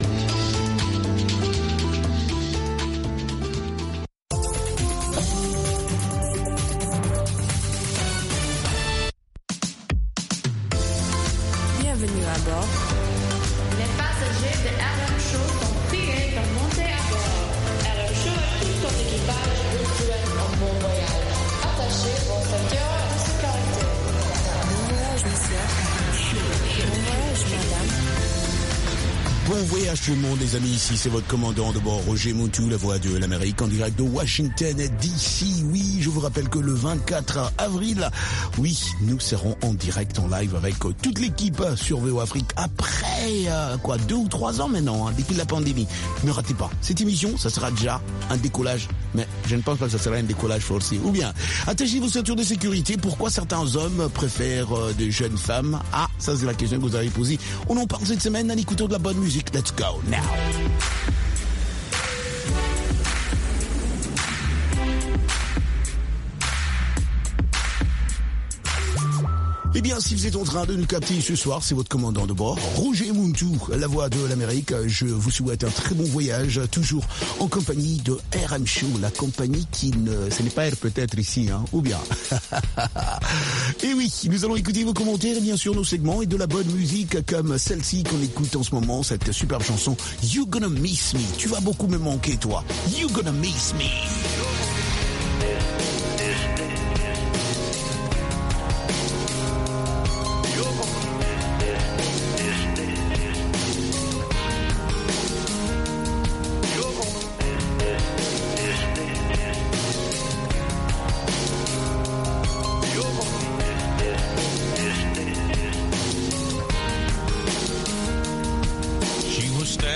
RM Show -Musique internationale & comedie